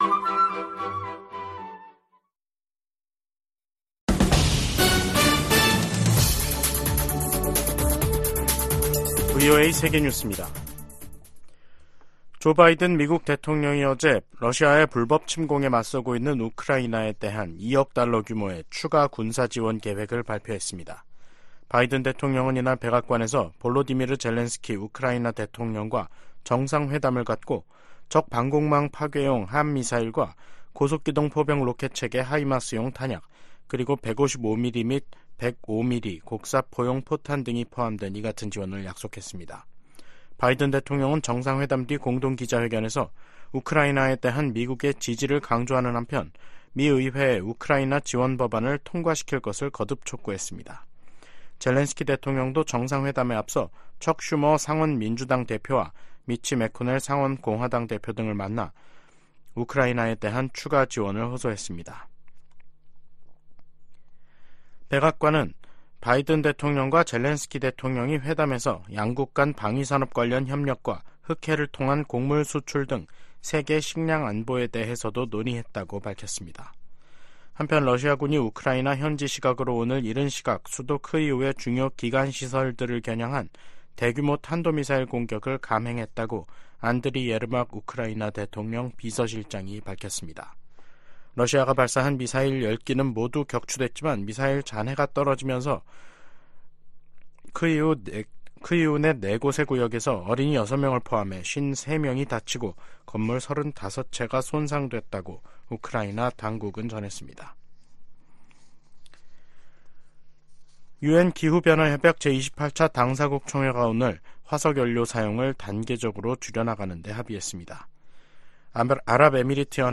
VOA 한국어 간판 뉴스 프로그램 '뉴스 투데이', 2023년 12월 13일 2부 방송입니다. 미국 재무부가 러시아 기업에 반도체 기술을 제공한 한국인을 제재했습니다. 미국이 북한 위협에 대응해 구축한 미사일 방어체계를 이용해 중거리탄도미사일을 공중 요격하는 시험에 성공했습니다.